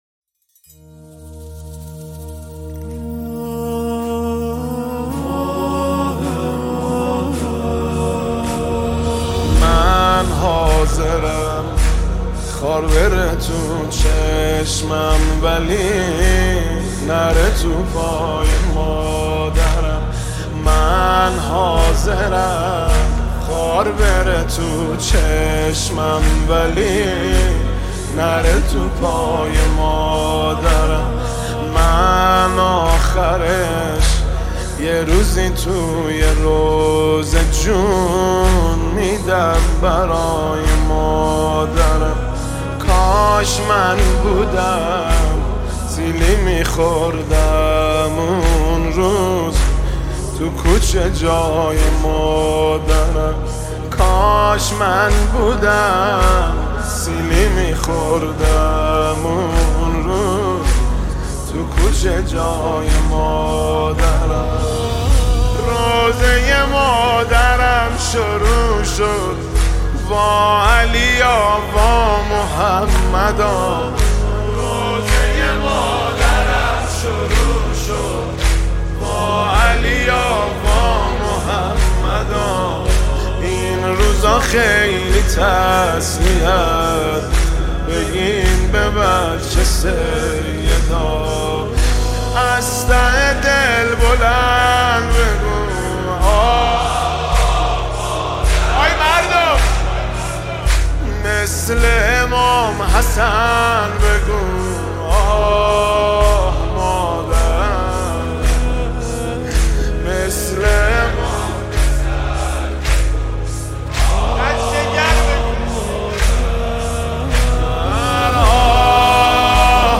مداحی مذهبی